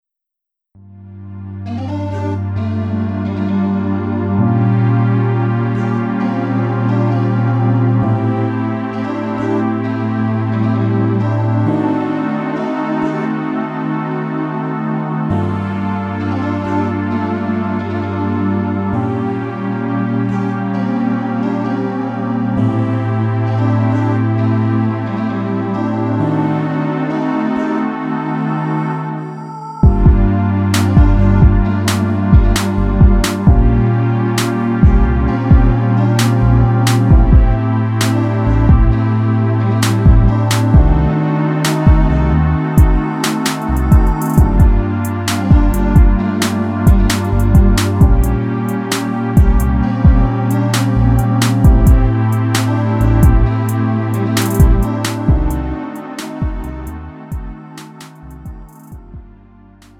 음정 원키 3:56
장르 구분 Lite MR